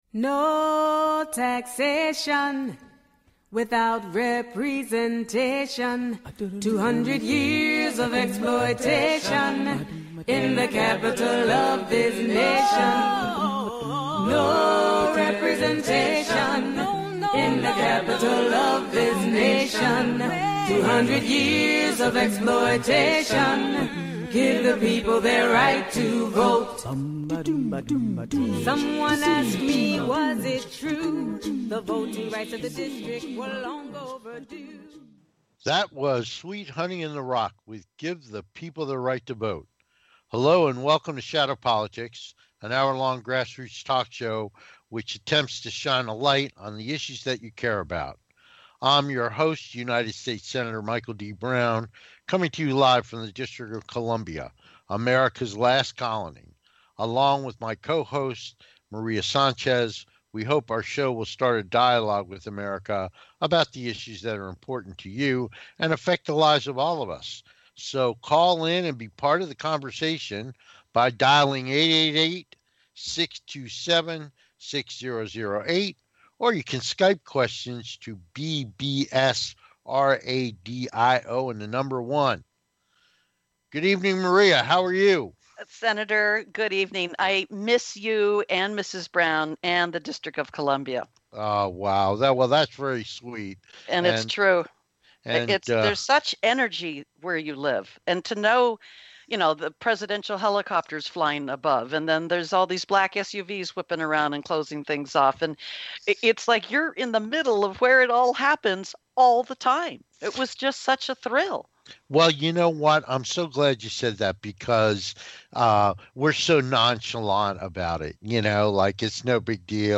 No guest today - just time to catch up with what's been going on in the world of politics and more!
Shadow Politics is a grass roots talk show giving a voice to the voiceless.